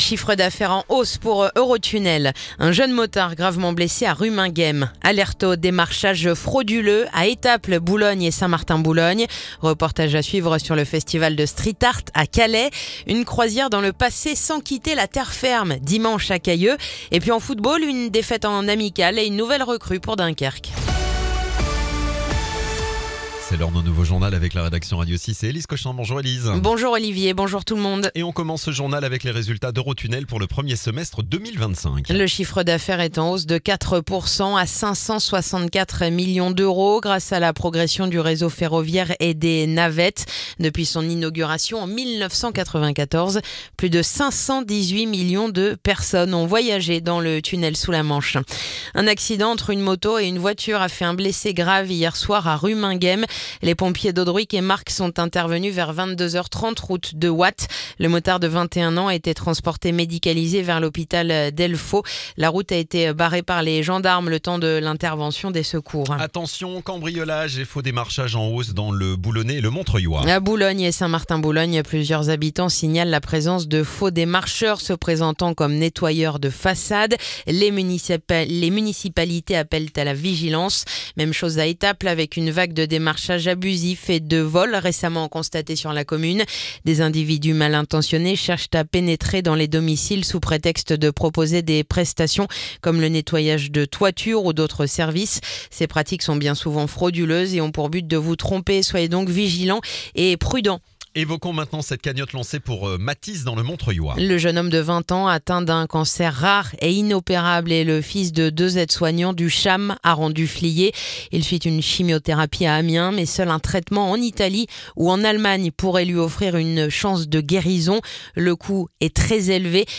Le journal du jeudi 24 juillet